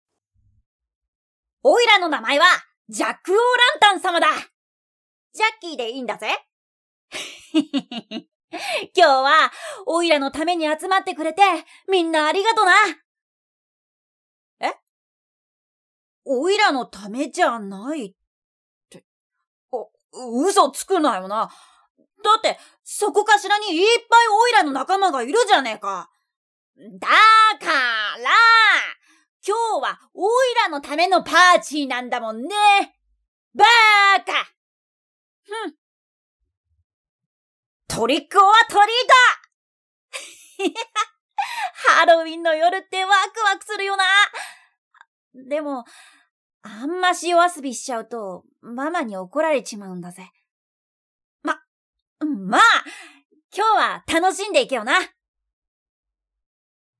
ジャックオーランタンの少年。
まだまだ遊びたい盛りのやんちゃ坊主